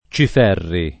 [ © if $ rri ]